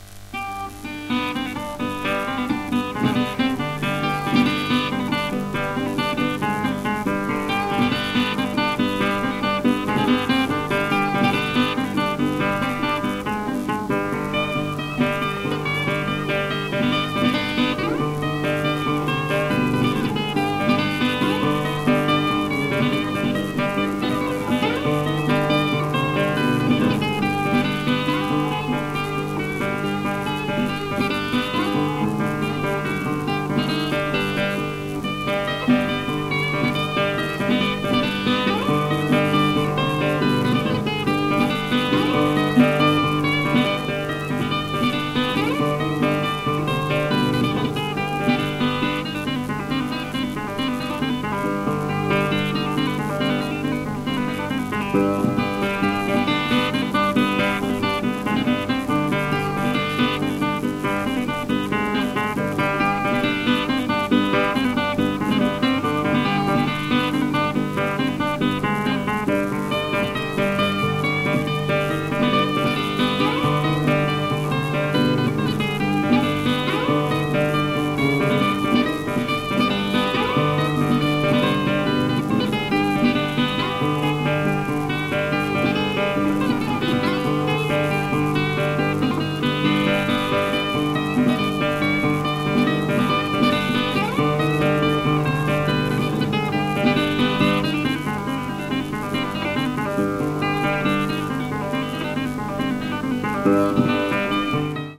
ギリシャのブルース“レンベーティカ”
※レコードの試聴はノイズが入ります。